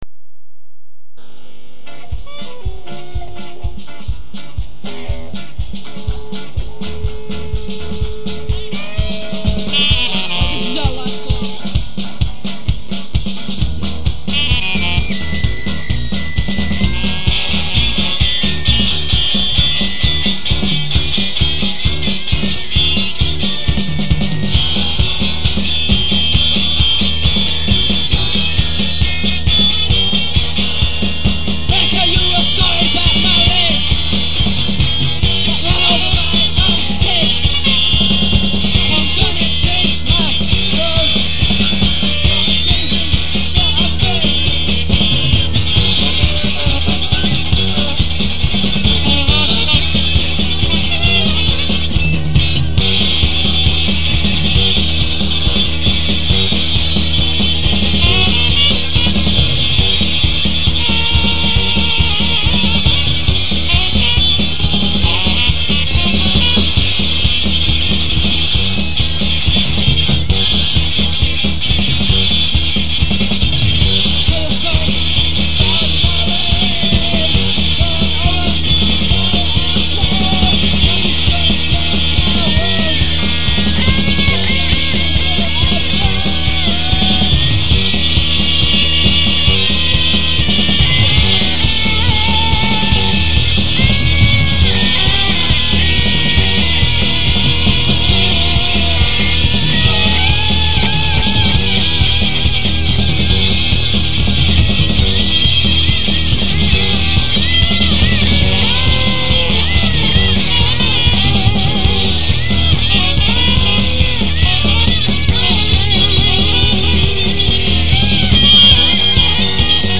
from the Gladstone, Christchurch, Dec. 31, 1983